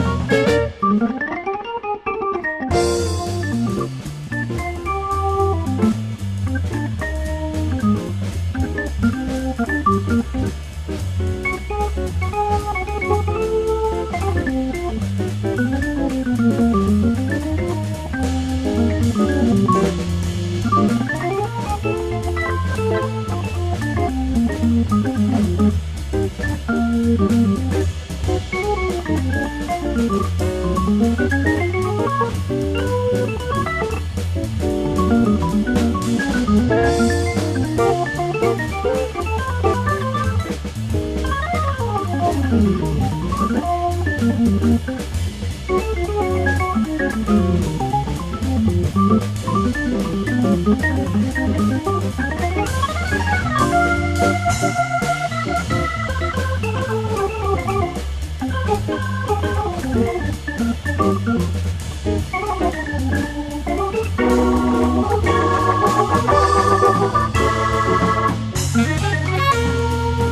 à la guitare